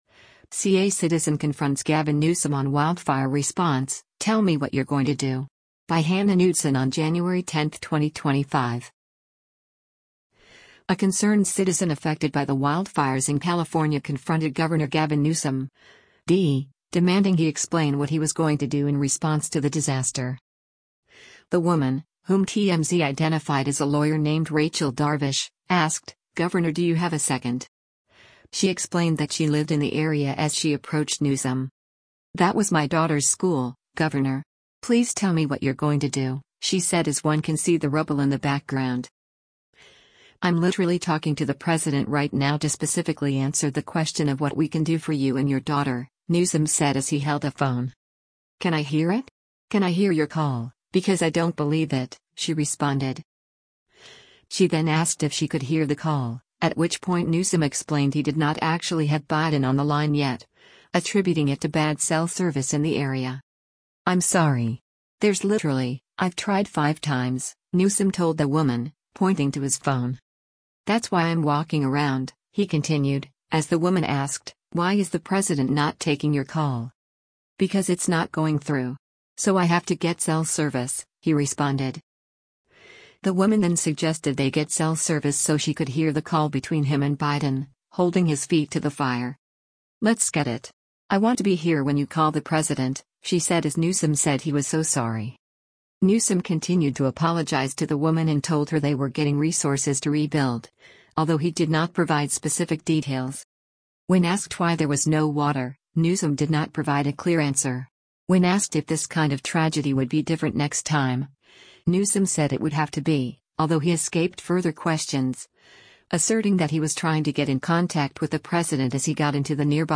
A concerned citizen affected by the wildfires in California confronted Gov. Gavin Newsom (D), demanding he explain what he was going to do in response to the disaster.